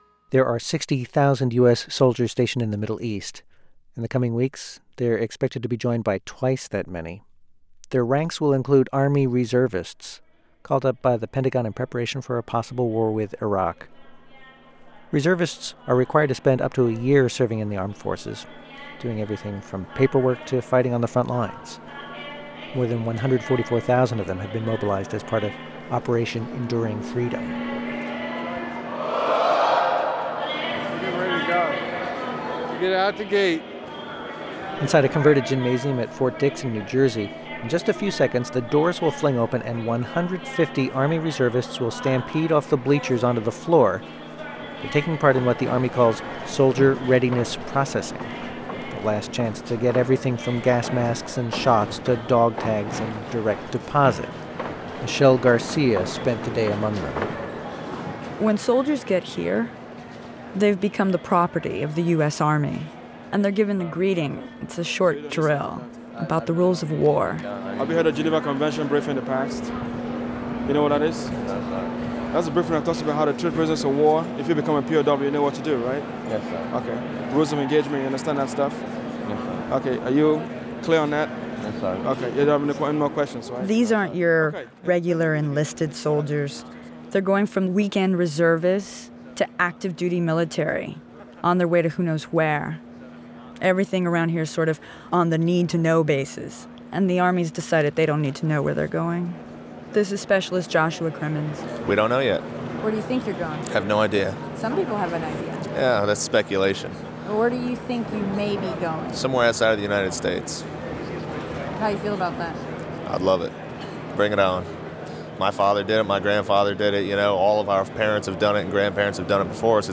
Ten years ago, I produced a feature story for public radio about Army Reservists as they prepared to deploy for Afghanistan.The piece was built around the drafting of their a last will and testament and centered on the singular question: “what was the most precious thing in their lives?”